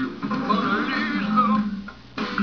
This is when they are in France or something and Taylor sings out "Mona Lisa."